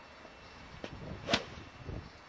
描述：在大约2英寸的雪地上用3根木头打高尔夫，没有球，只是敲打雪地。